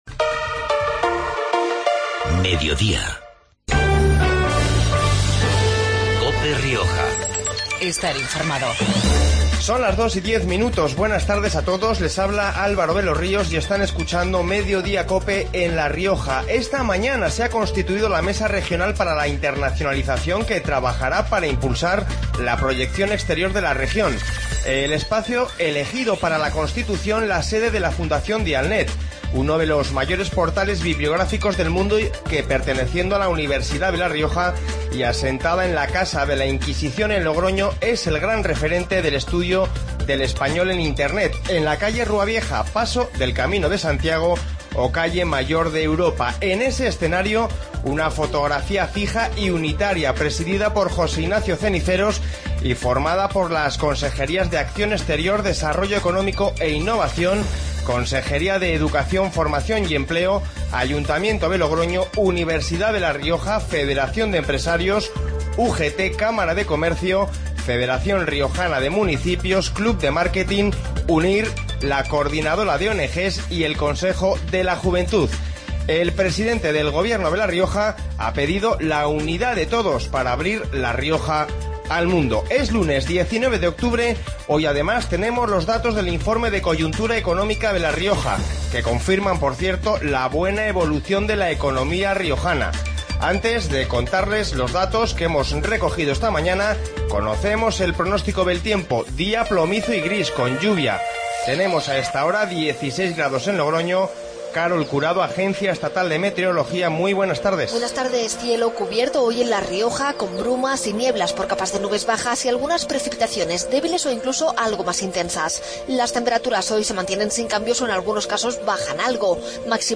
Informativo Medidodia en La Rioja 20-10-15